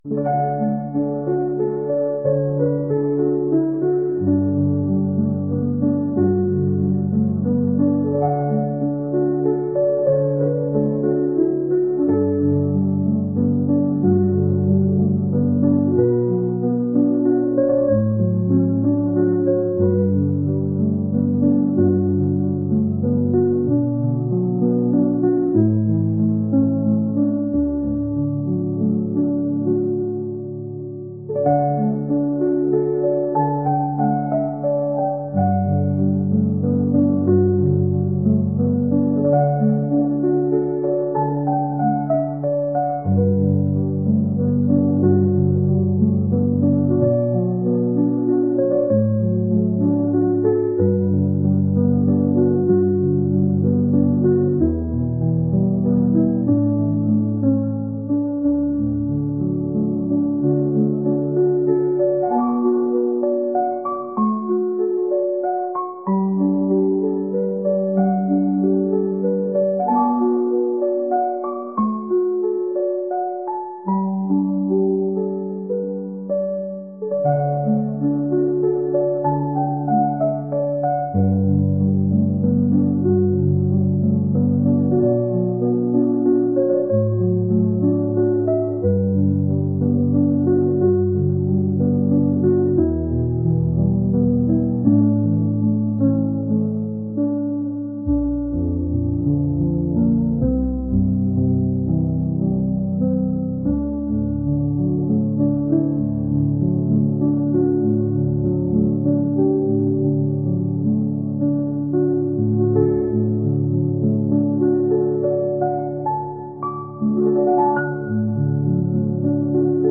癒し、リラックス